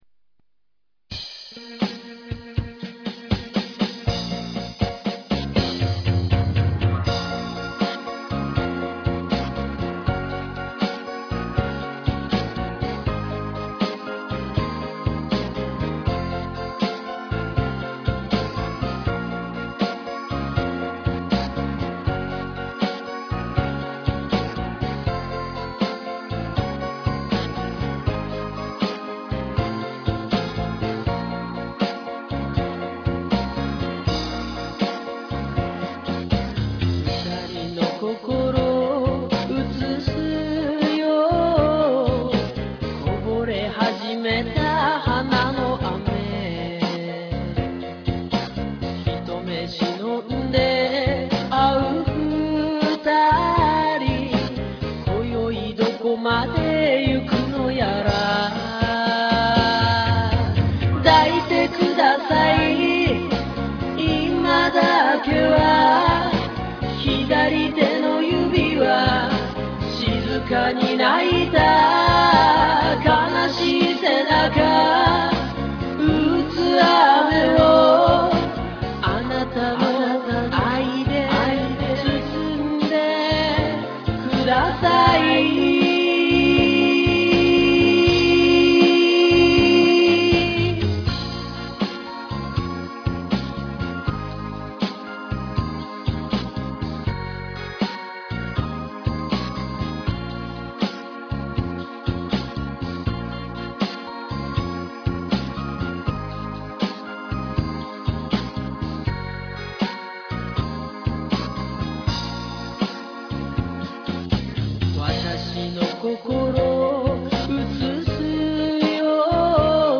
COMMENT真剣に演歌をやってみました。
レコーディングも気合でフルデジタル。